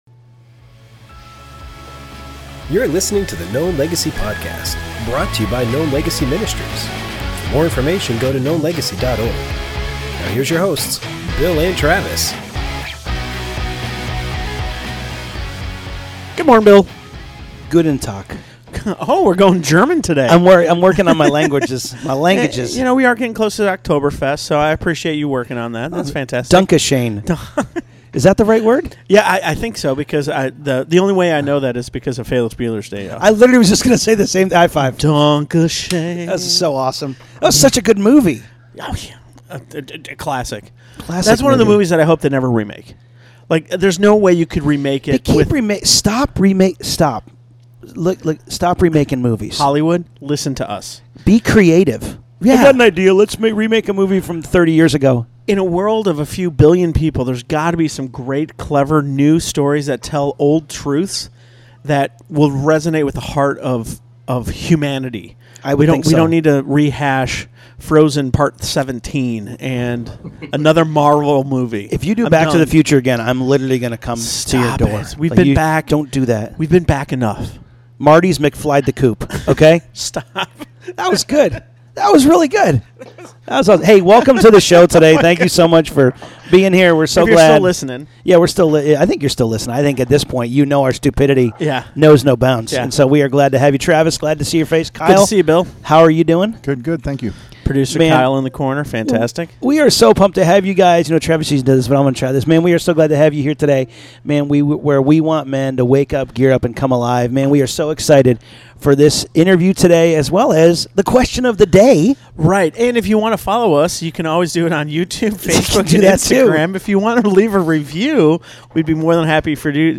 This interview shares insight about: